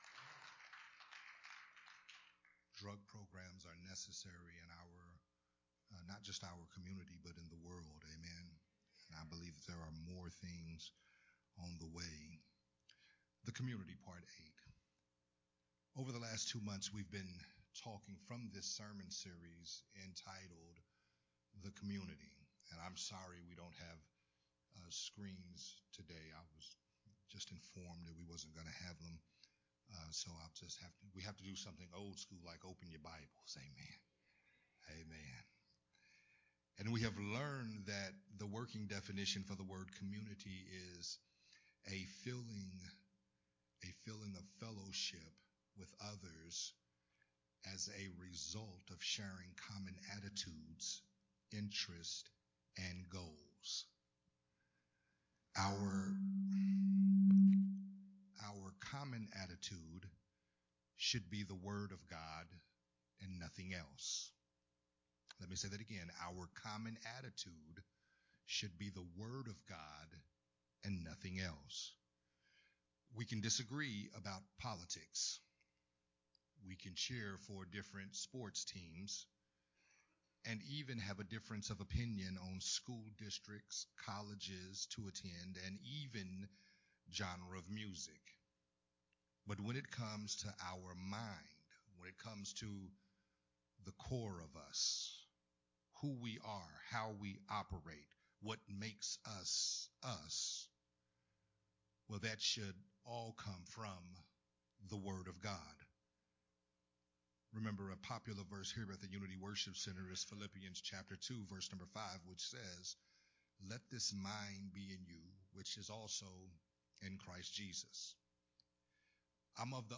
Part 8 of the sermon series, “Community”
and recorded at Unity Worship Center on November 20, 2022